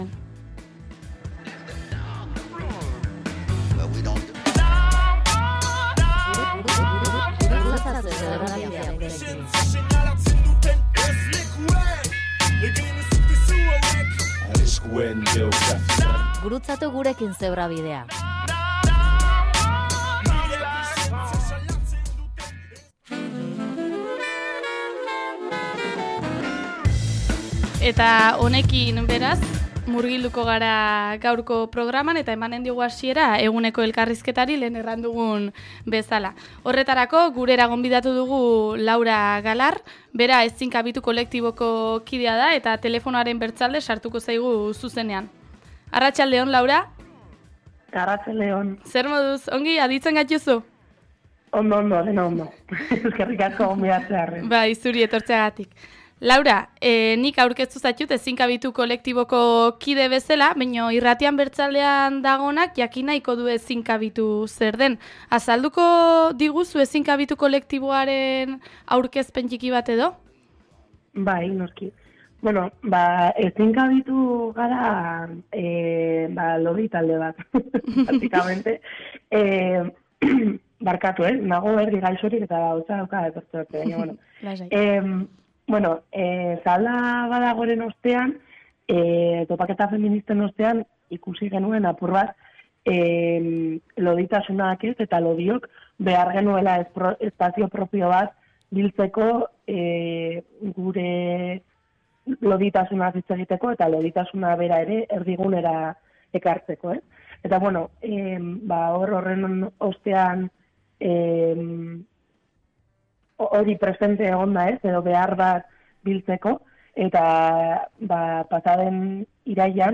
Eguneko elkarrizketa